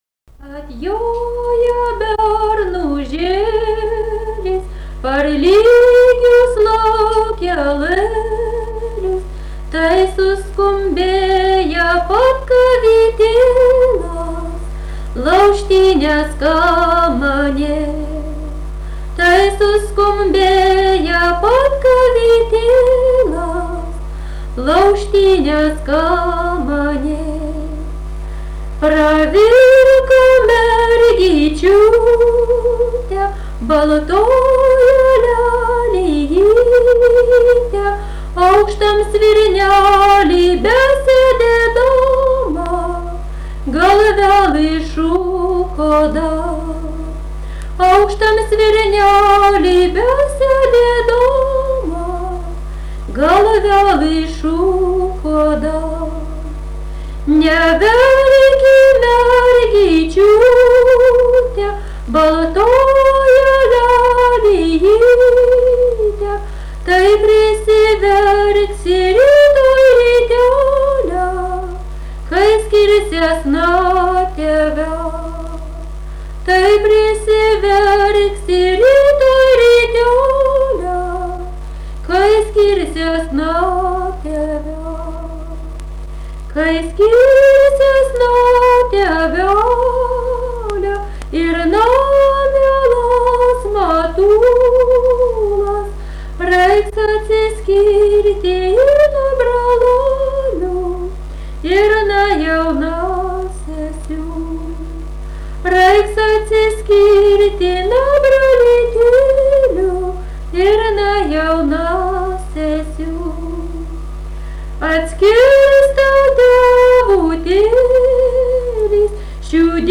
Dalykas, tema daina
Erdvinė aprėptis Juodšiliai Vilnius
Atlikimo pubūdis vokalinis